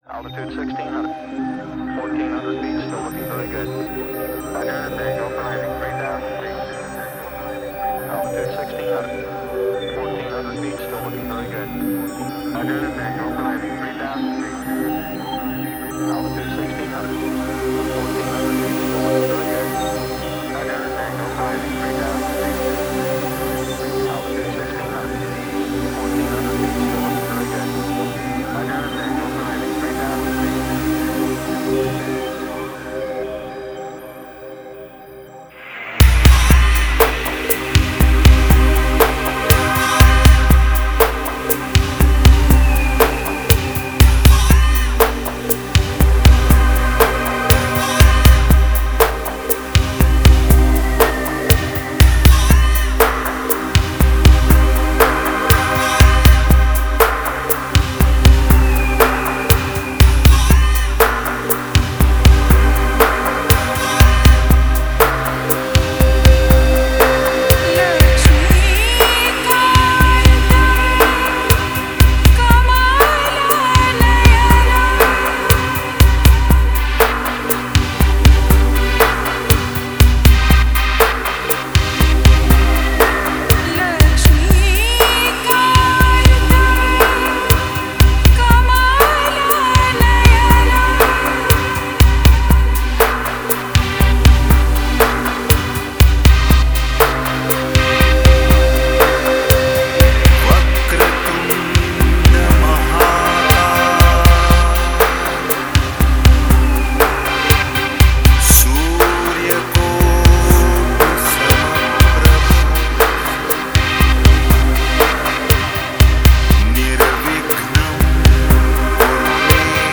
New Age Мистическая музыка Ambient